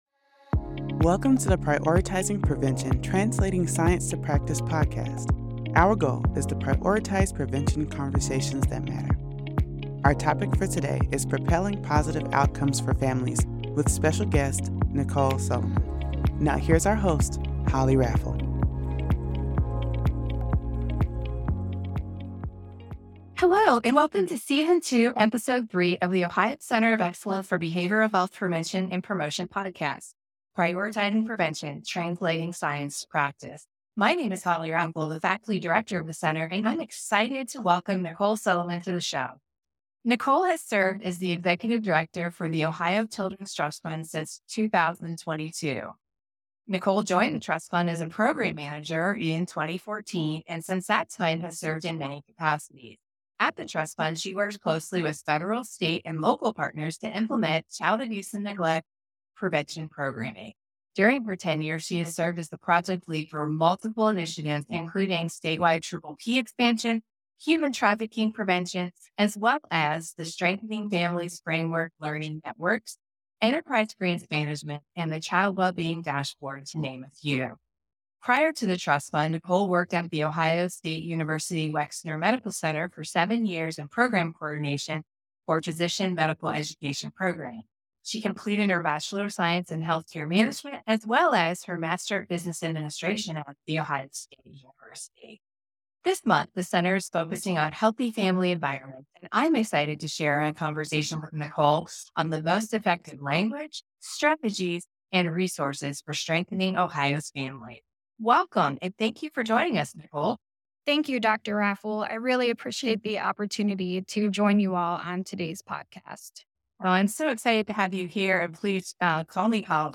They discuss the importance of using outcomes-focused language when communicating support and programs with parents and families. This episode highlights a framework of protective factors for the well-being of parents and children and the intersection of social determinants of health and risk factors like parental stress. Tune in to the conversation to learn more about the landscape of support for parents in Ohio and strategies to promote healthy family environments.